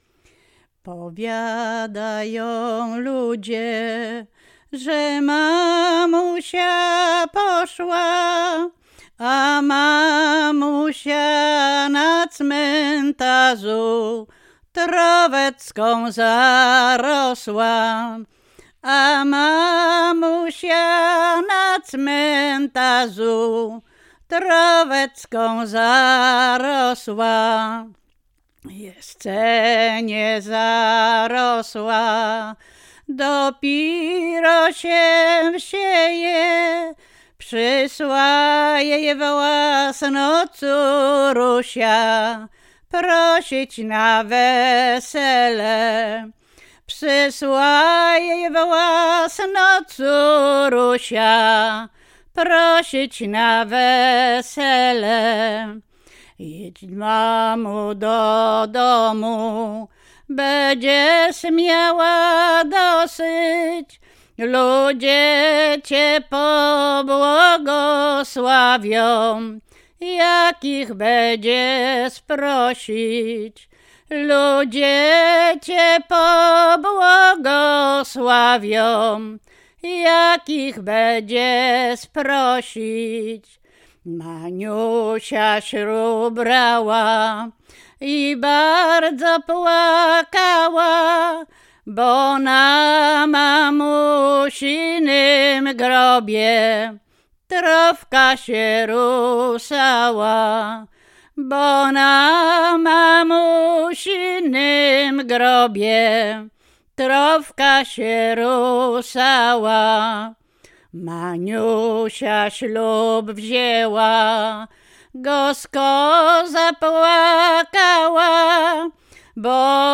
Łęczyckie
Weselna sieroca